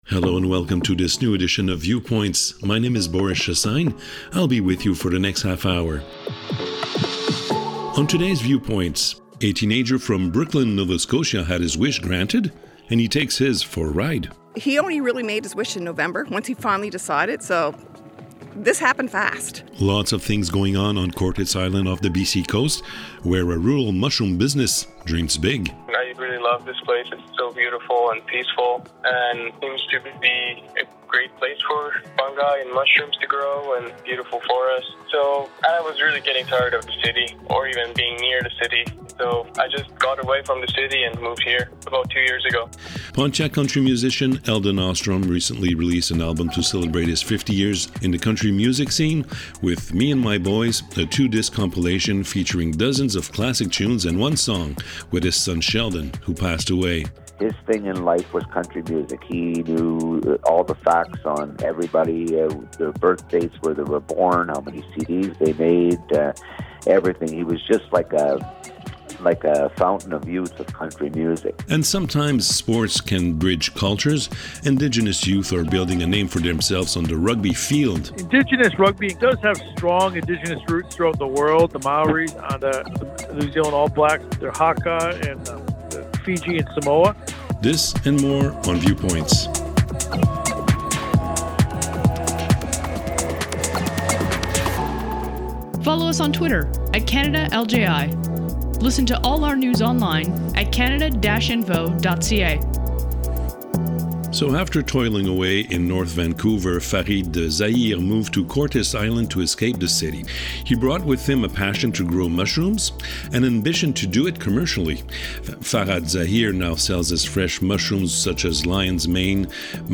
The Community Radio Fund of Canada produces a new weekly radio series called Viewpoints, a 30 minute news magazine aired on 30 radio stations across Canada. Viewpoints provides an overview of what’s happening across the country, thanks to some 20 radio correspondents working for the Local Journalism Initiative in British Columbia, Alberta, Ontario, Québec, New Brunswick and Nova Scotia.